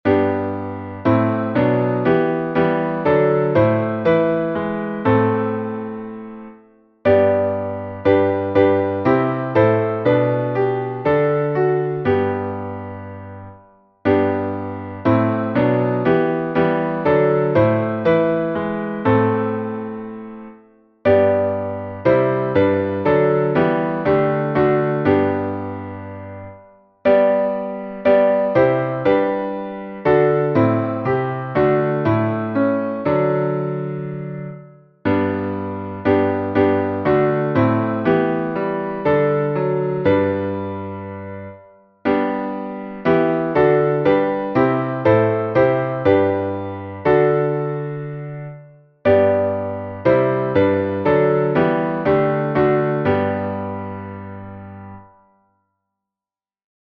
Modo: hipojônio
salmo_98A_instrumental.mp3